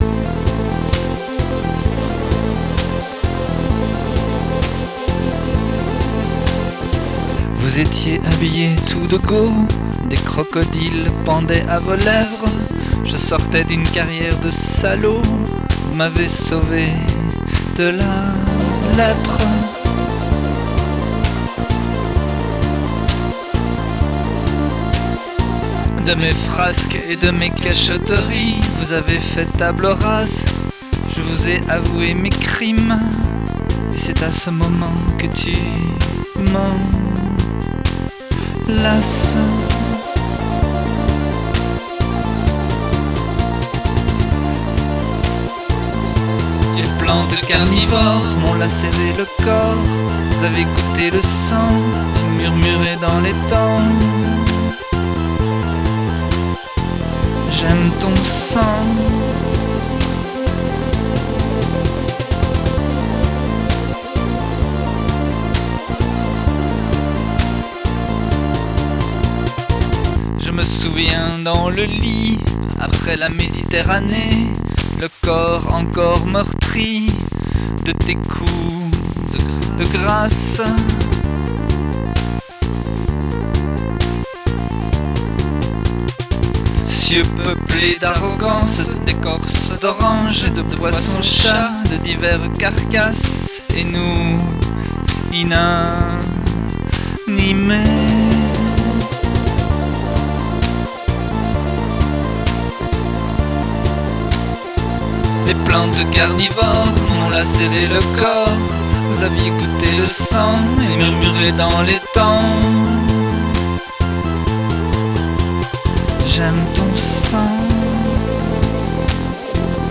Une chanson d’amour saignante. C’est une démo, enregistrée il y a quelques années, avec une électribe et une petite boîte à rythme mfb.